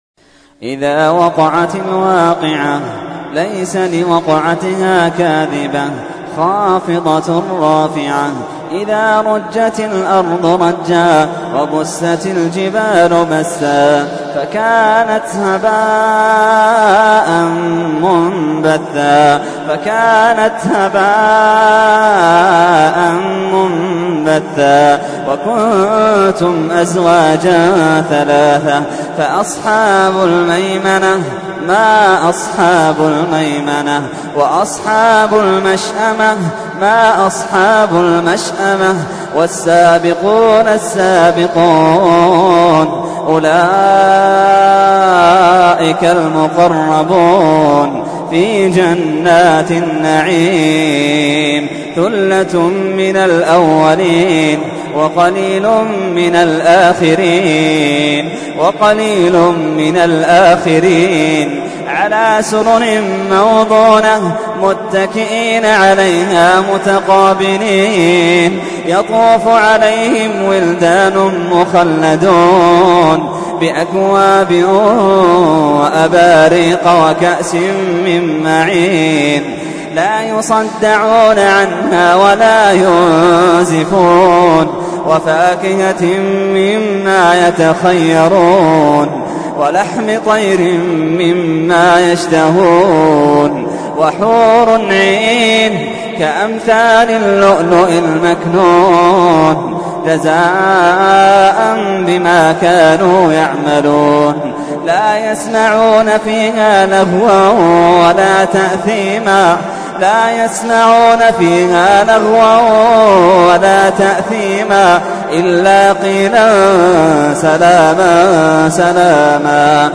تحميل : 56. سورة الواقعة / القارئ محمد اللحيدان / القرآن الكريم / موقع يا حسين